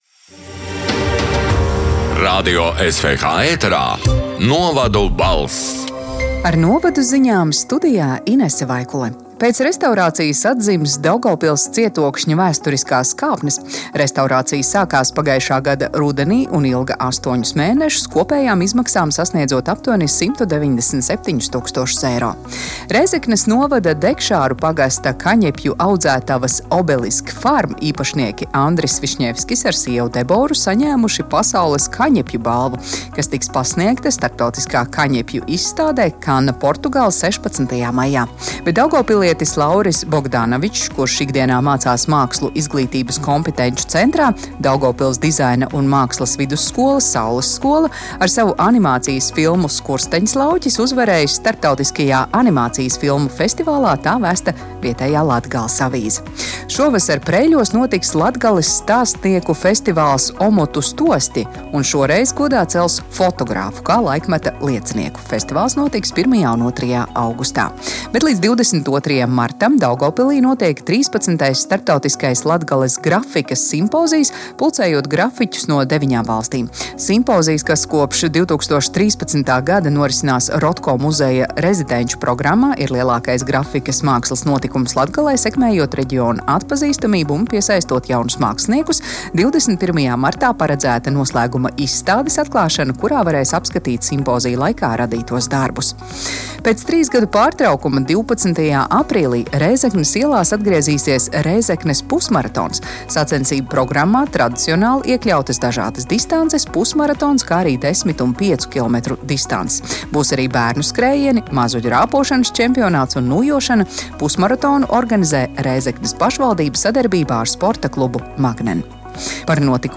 “Novadu balss” 14. marta ziņu raidījuma ieraksts: